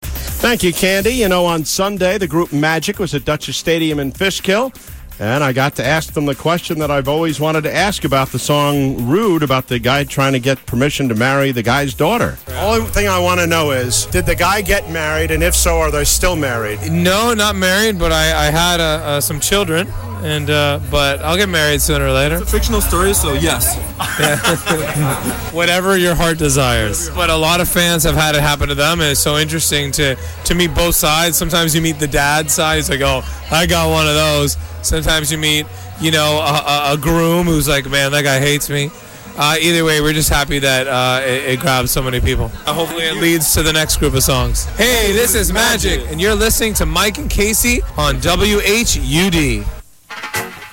Interview with the band Magic!